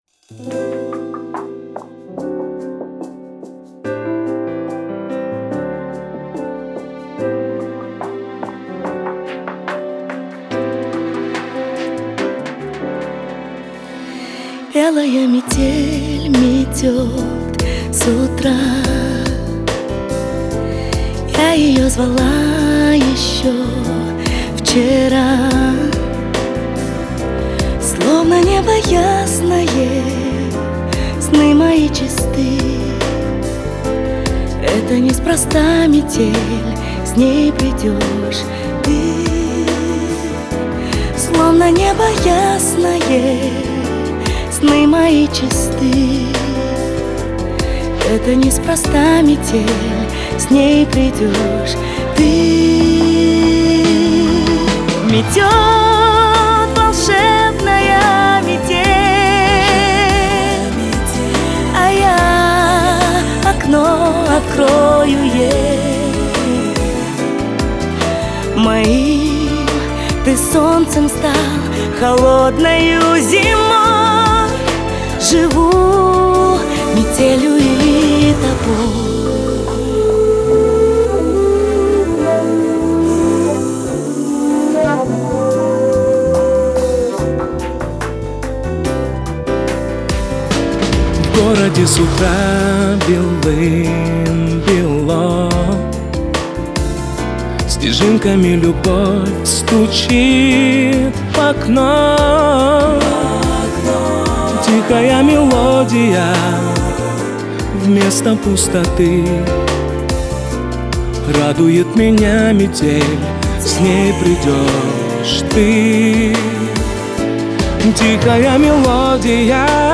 это эмоциональная поп-баллада